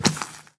Minecraft Version Minecraft Version snapshot Latest Release | Latest Snapshot snapshot / assets / minecraft / sounds / mob / husk / step1.ogg Compare With Compare With Latest Release | Latest Snapshot
step1.ogg